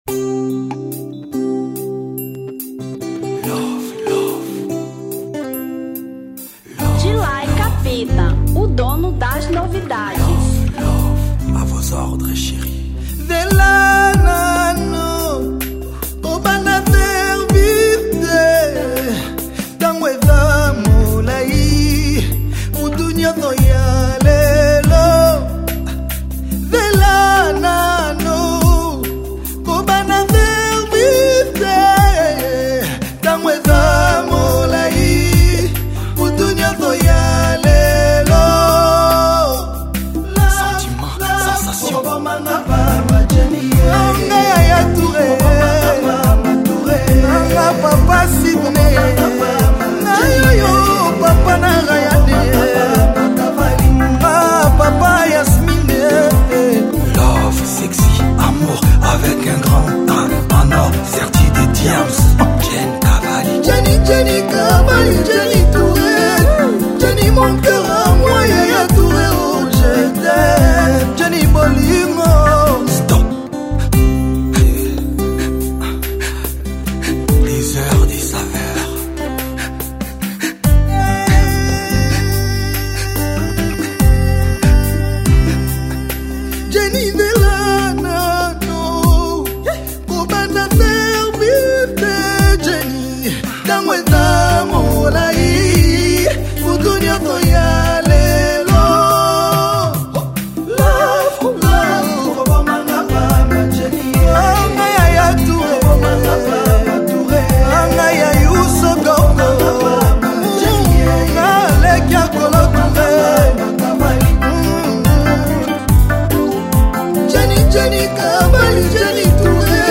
Rumba 2013